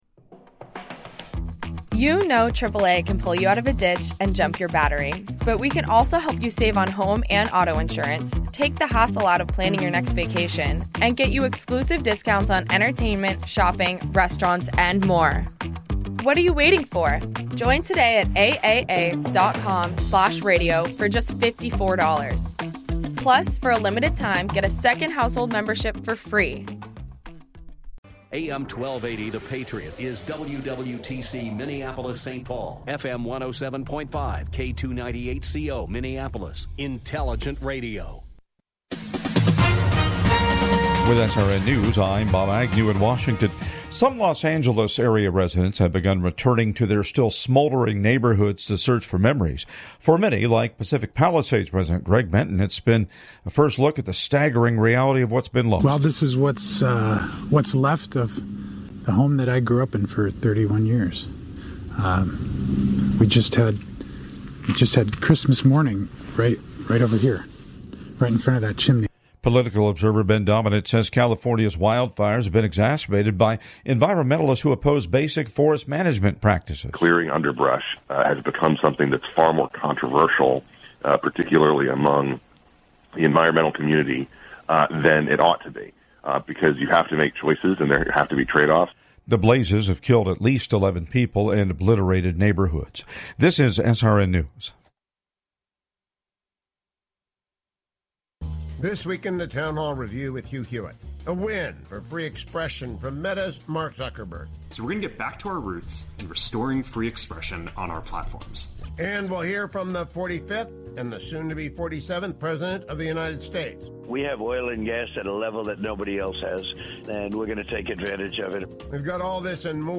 Discover his journey, learn valuable pitching tips, and hear stories that will inspire both athletes and sports enthusiasts. Don’t miss this engaging conversation with a true sportsman!